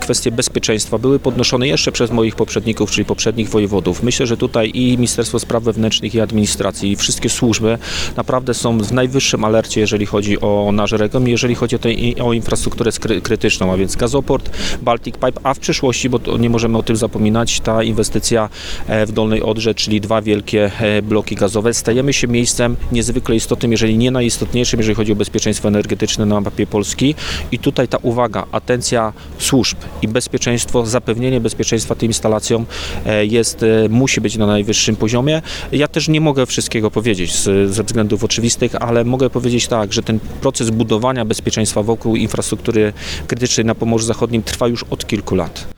– Ten proces wymaga spokoju i ciszy – mówi Zbigniew Bogucki, Wojewoda Zachodniopomorski.